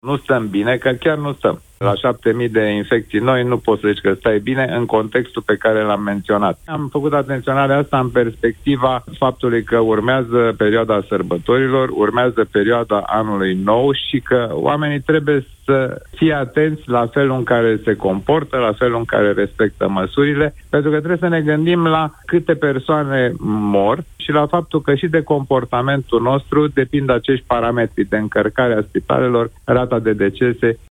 în emisiunea Deşteptarea la Europa Fm